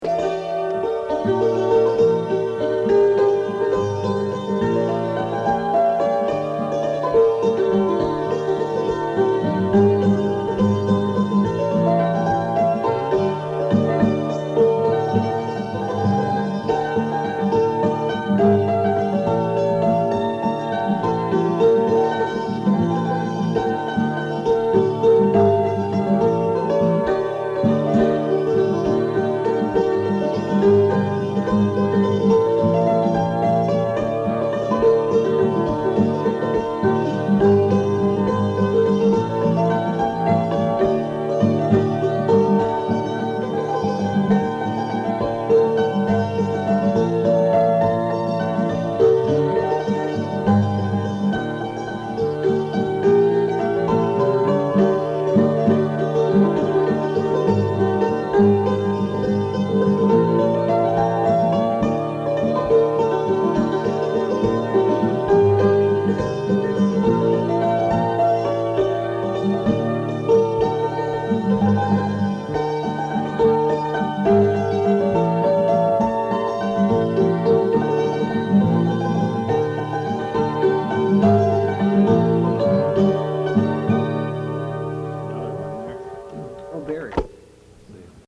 fiddle